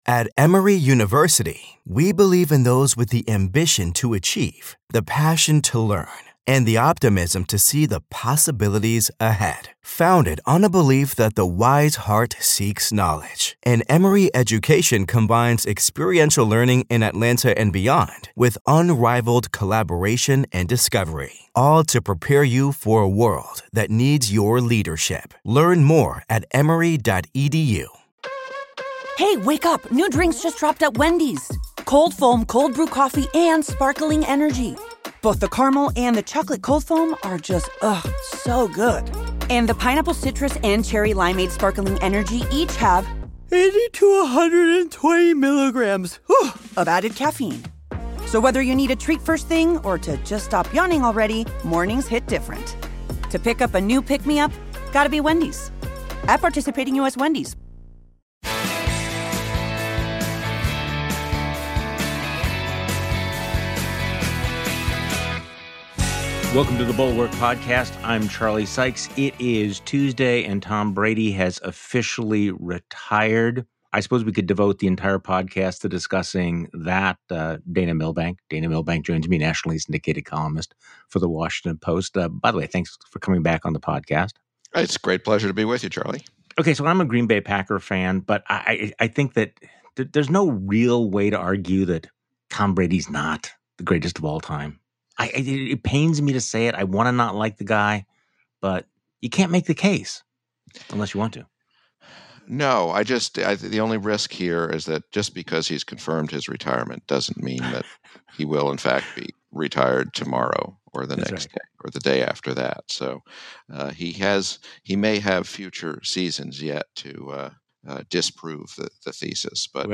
The Washington Post's Dana Milbank joins Charlie Sykes on today's podcast.